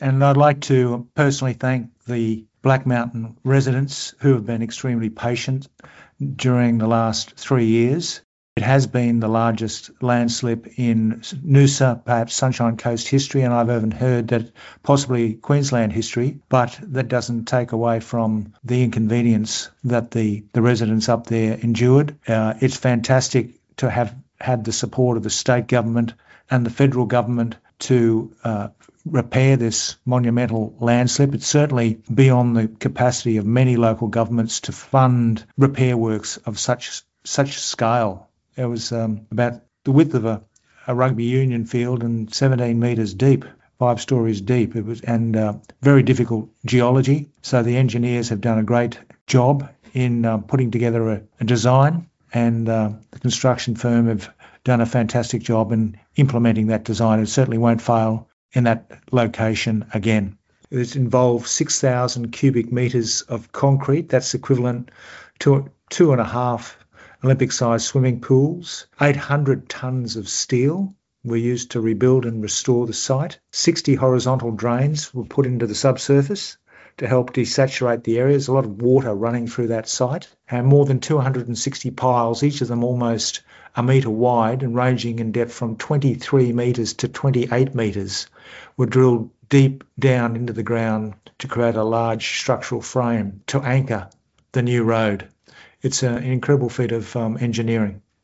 Noosa Mayor Frank Wilkie discusses the Black Mountain Road landslide repair project:
mayor-frank-wilkie-black-mountain-landslide-repair-completion.mp3